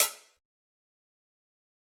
kits/OZ/Closed Hats/Hihat (Old$chool).wav at ts
Hihat (Old$chool).wav